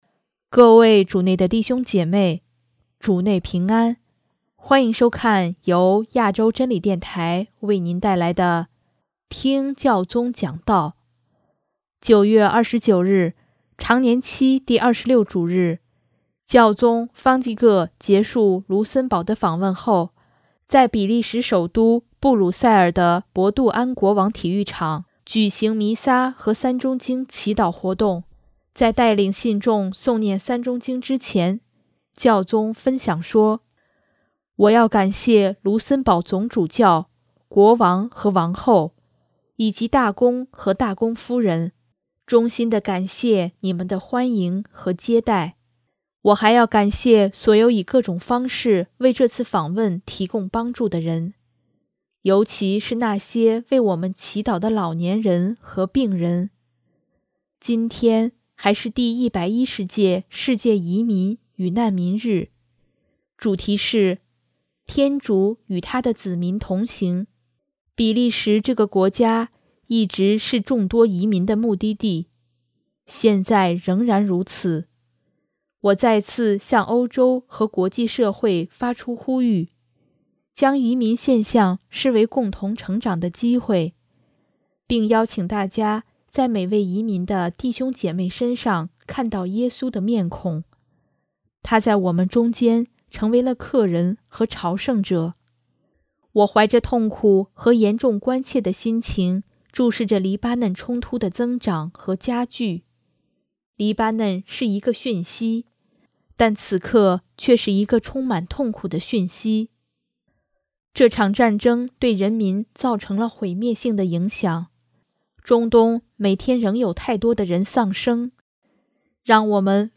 【听教宗讲道】|将移民现象视为共同成长的机会
9月29日，常年期第二十六主日，教宗方济各结束卢森堡的访问后，在比利时首都布鲁塞尔的“博杜安国王”体育场举行弥撒和《三钟经》祈祷活动。